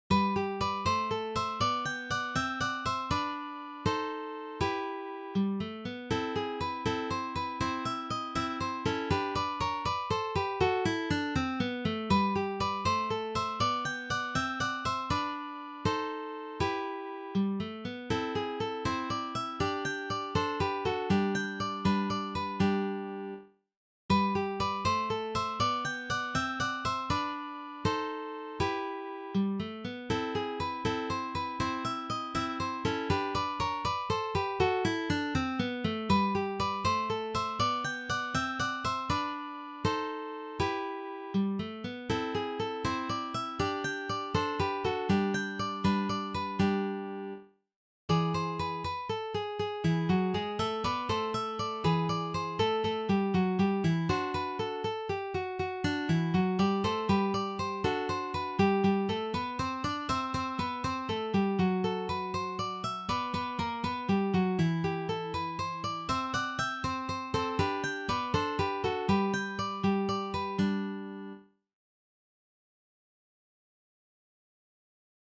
for three guitars